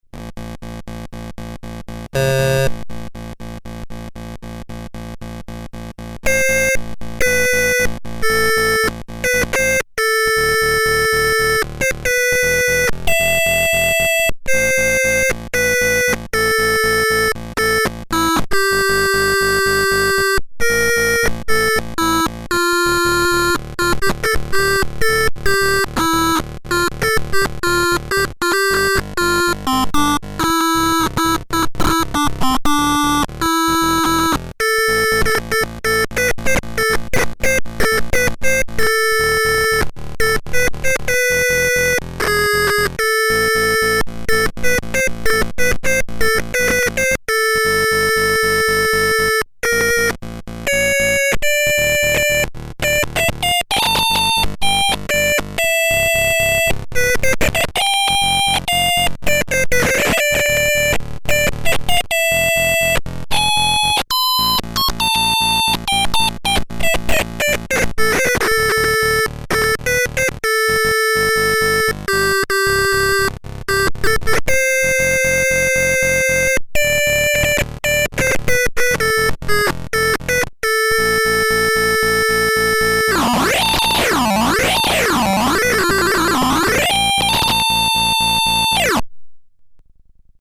Двухголосый стилофон.
Stilofon_audio.mp3